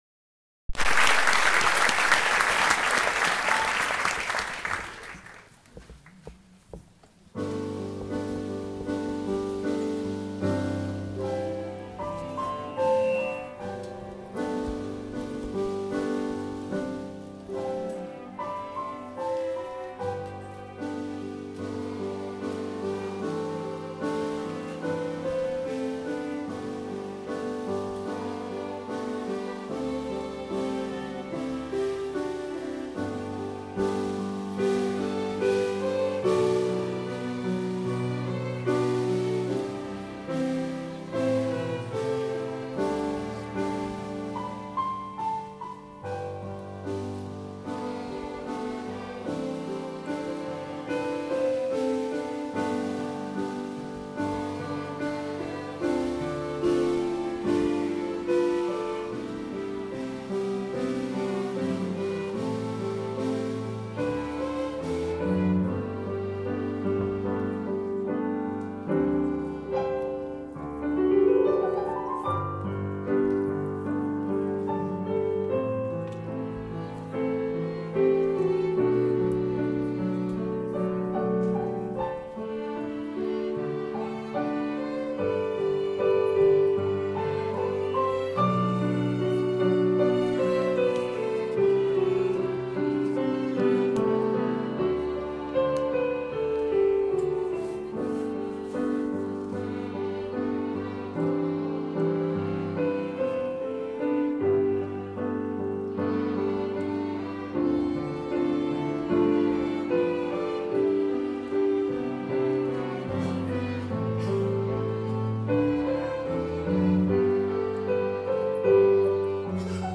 strings ensemble